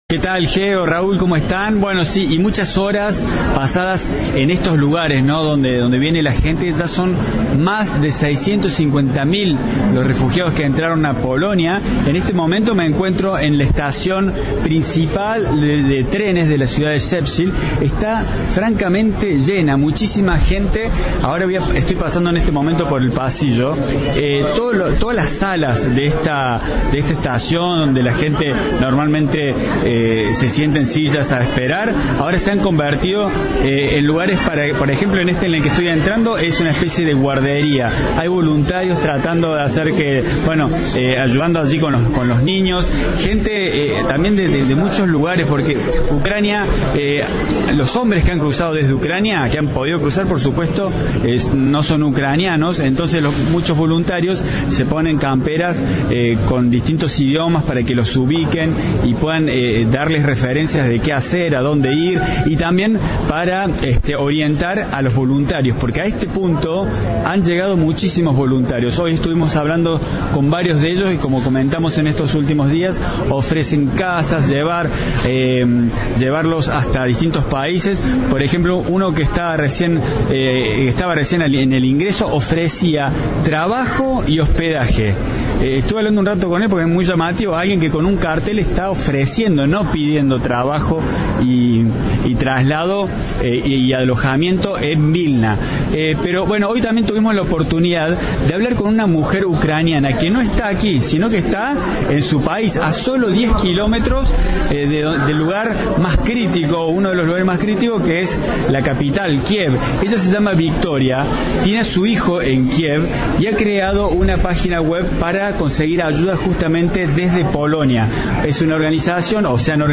Audio. Desde Ucrania, una mujer organiza una colecta en Polonia